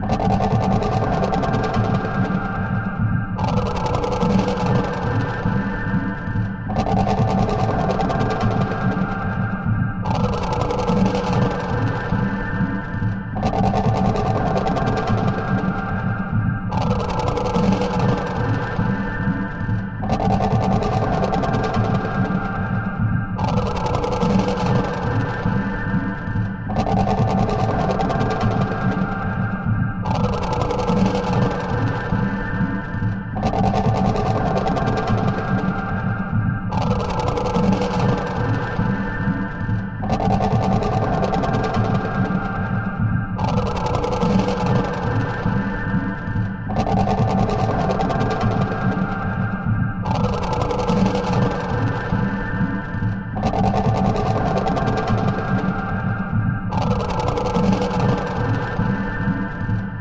Speed 120%